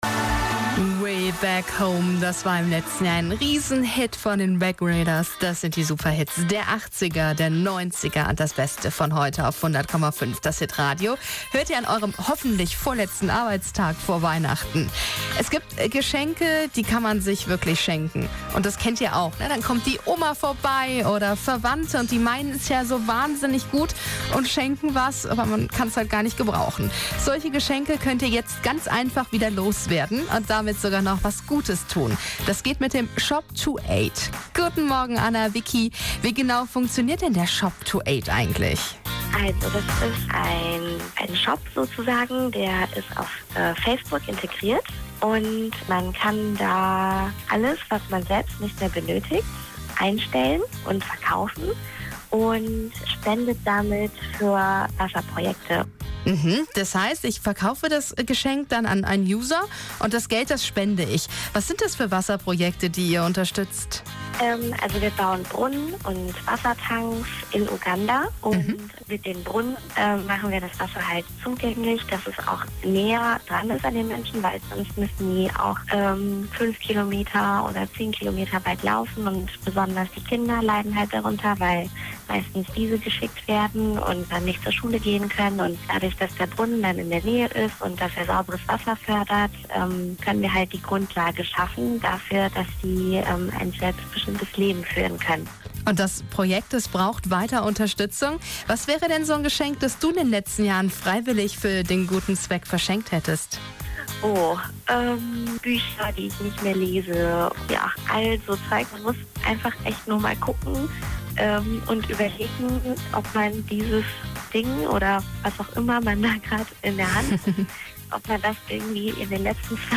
Hier findet ihr das Interview: